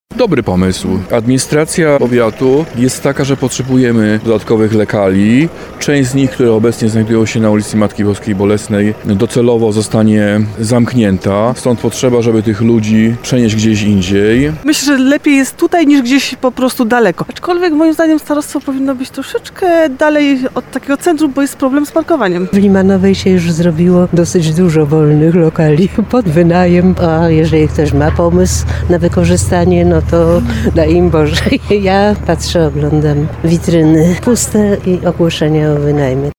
2sonda_limanowa.mp3